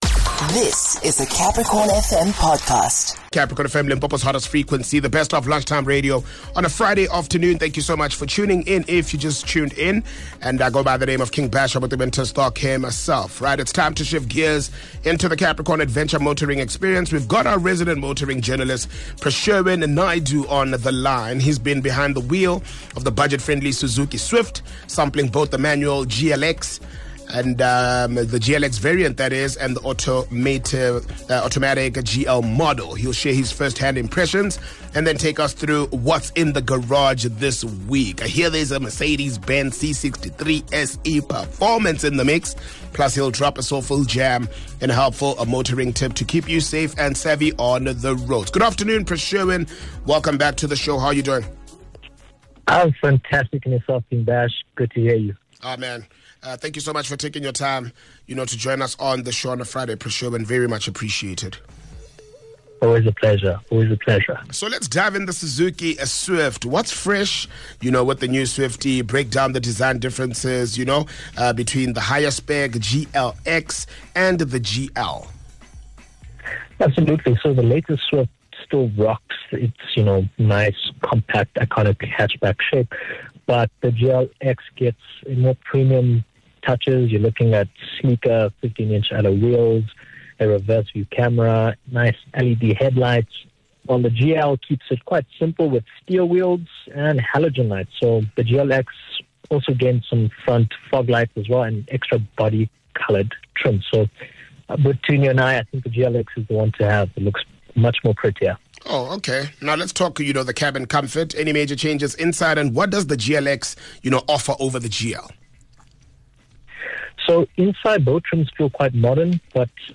joined on the line by motoring journalist